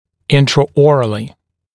[ˌɪntrə»ɔːrəlɪ][ˌинтрэ’о:рэли]внутриротовым методом; внутриротовым доступом (хир.)